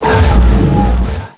pokeemerald / sound / direct_sound_samples / cries / golurk.aif